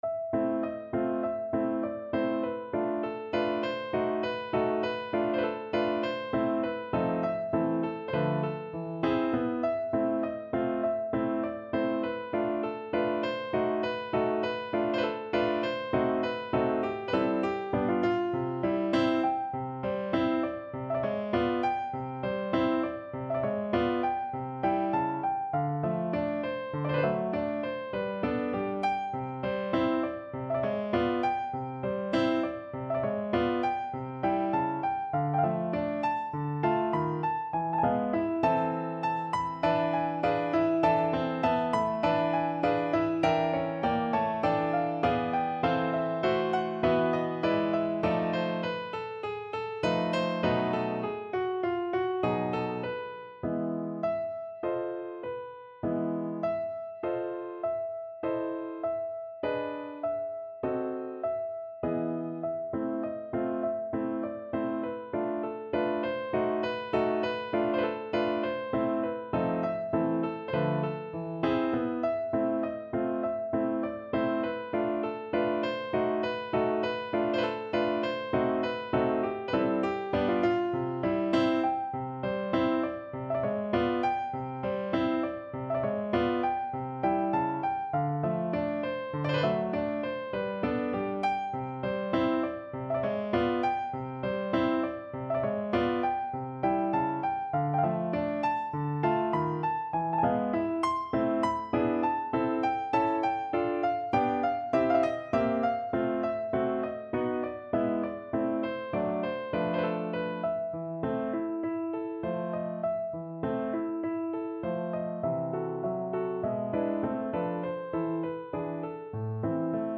No parts available for this pieces as it is for solo piano.
Allegro moderato
2/4 (View more 2/4 Music)
Classical (View more Classical Piano Music)